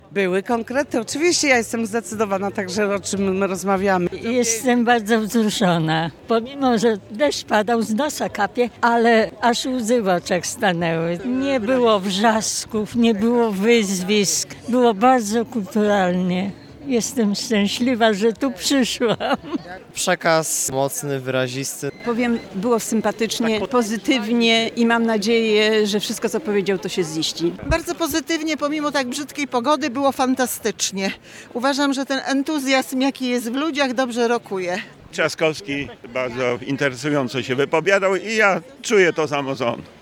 Mimo padającego tego dnia deszczu, zwolennicy opozycji tłumnie przybyli na Wały Chrobrego, a spotkanie określali w samych superlatywach.